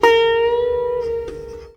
SITAR LINE63.wav